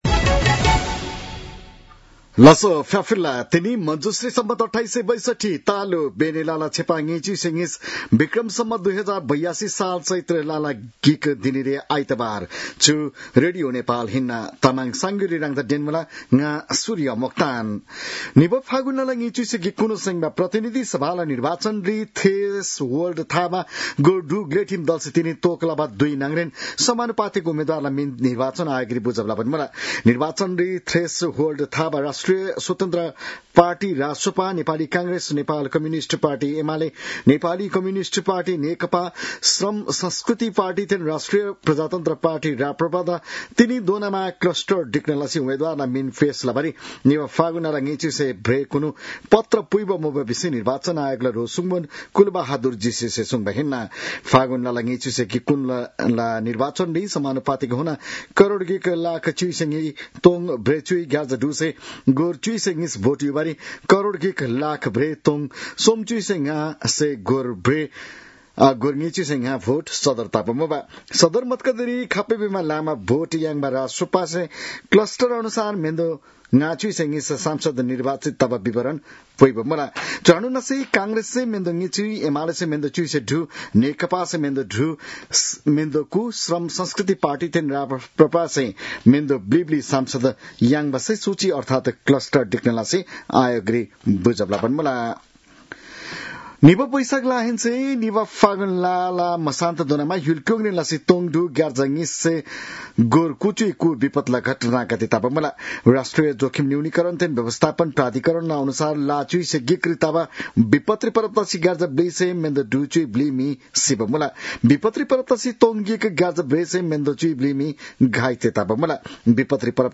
तामाङ भाषाको समाचार : १ चैत , २०८२
Tamang-news-12-01.mp3